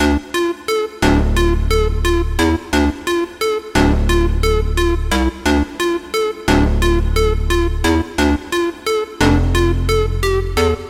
描述：朴实无华
标签： 88 bpm Electronic Loops Synth Loops 1.84 MB wav Key : Unknown
声道立体声